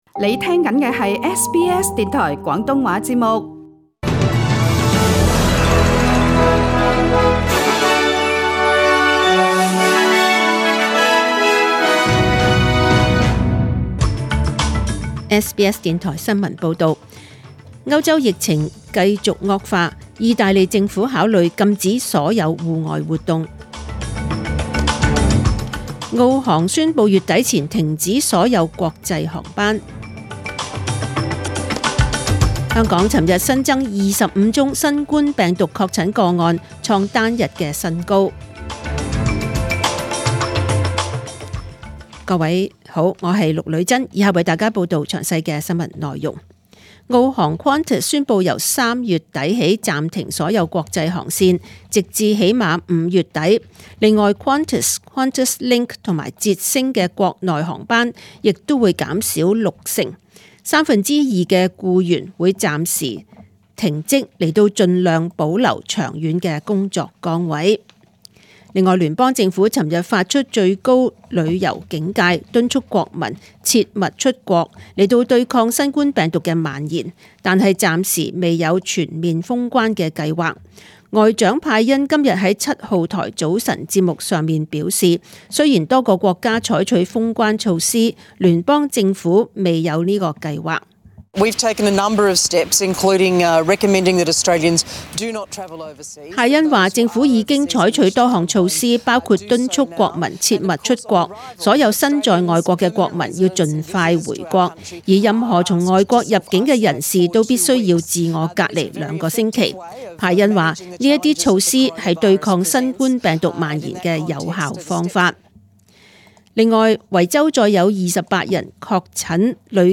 请收听本台为大家准备的详尽早晨新闻